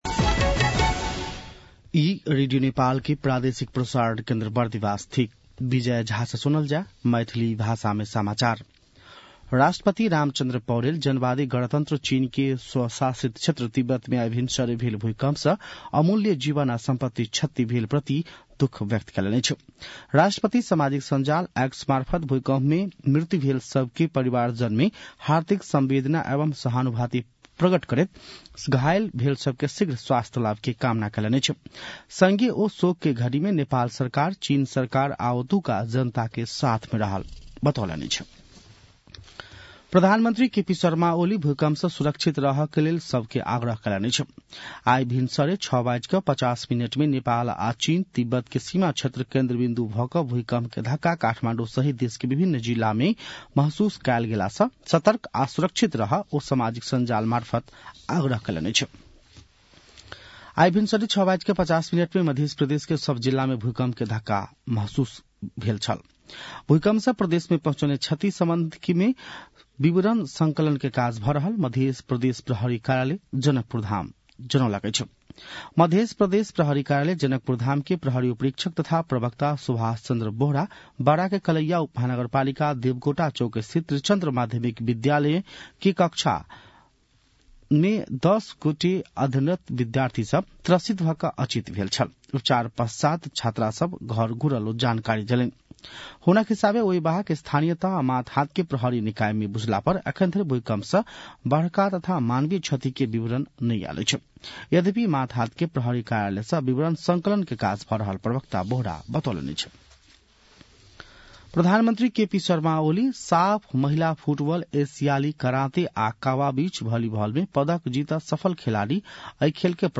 An online outlet of Nepal's national radio broadcaster
मैथिली भाषामा समाचार : २४ पुष , २०८१
Maithali-news-9-23.mp3